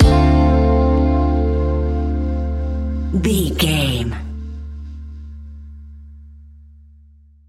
Ionian/Major
chilled
laid back
sparse
chilled electronica
ambient
atmospheric
instrumentals